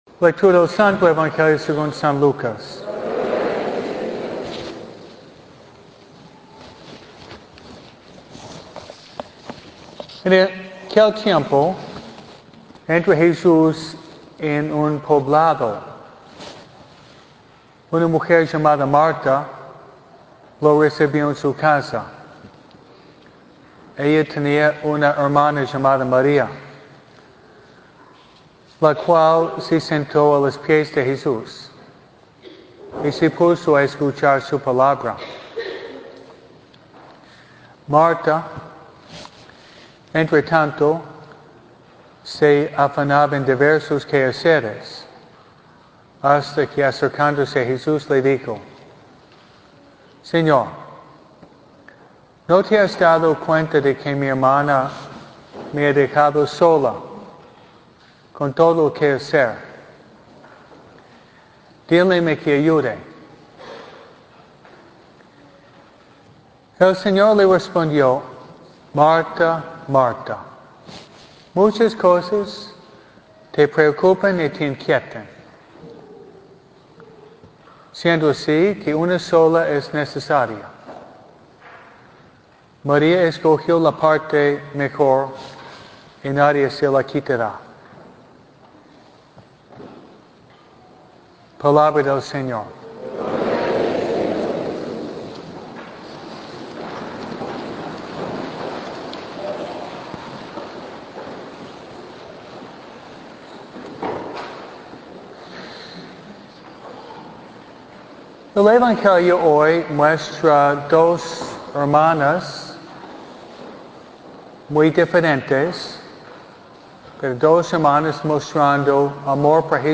MISA – RECIBE A JESUS EN TU CASA.